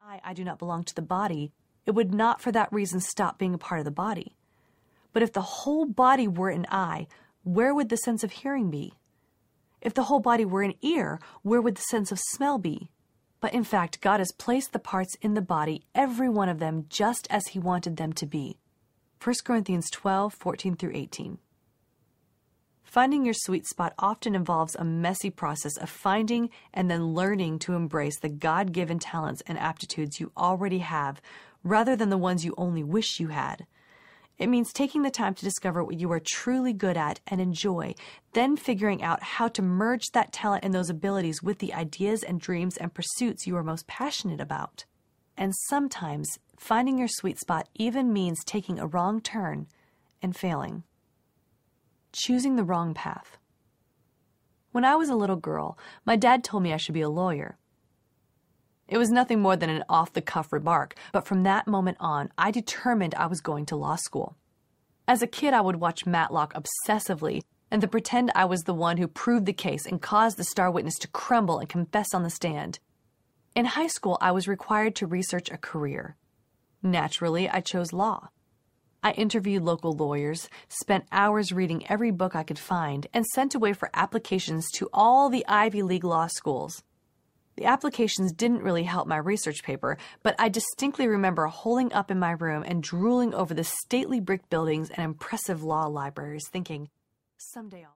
Living Well, Spending Less Audiobook
Narrator
5.27 Hrs. – Unabridged